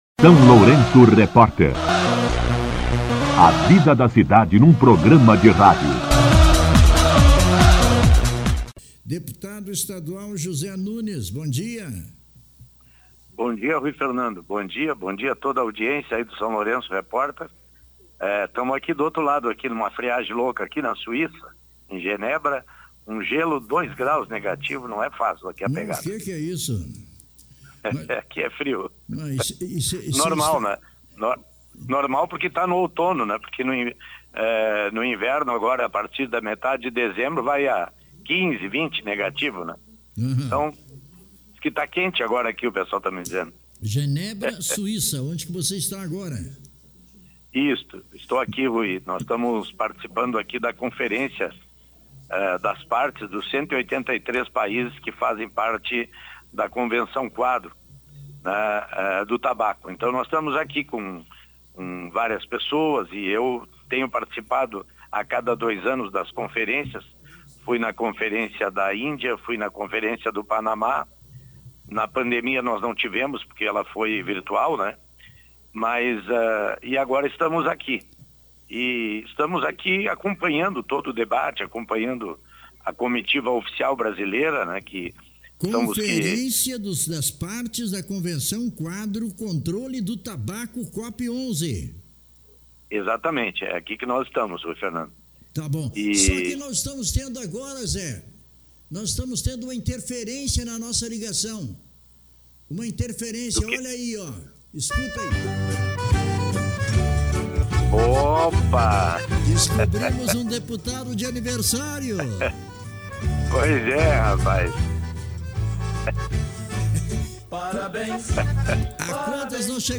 O deputado estadual Zé Nunes conversou com exclusividade com o SLR Rádio na manhã desta quarta-feira (19).
Entrevista com O deputado estadual Zé Nunes